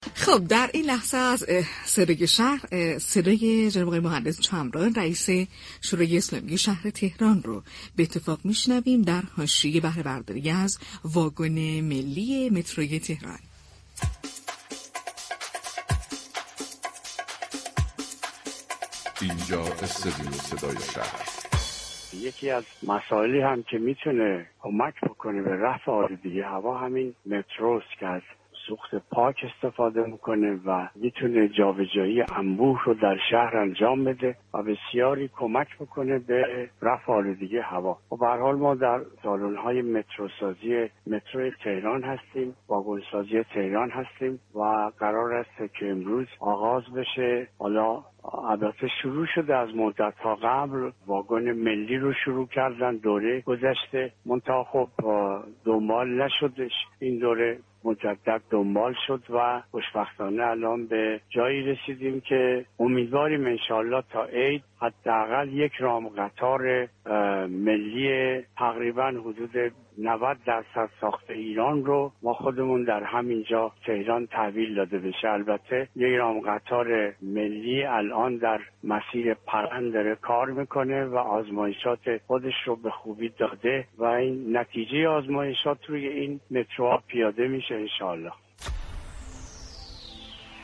به گزارش پایگاه اطلاع رسانی رادیو تهران، مهدی چمران، رئیس شورای اسلامی شهر تهران در گفت و گو با «بام تهران» اظهار داشت: قرارداد تولید انبوه نخستین نمونه قطار ملی مترو، بین شركت مترو تهران و شركت واگن‌سازی تهران منعقد شده بود كه طبق این قرارداد امروز 24 آذر خط تولید انبوه قطار ملی مترو افتتاح شد.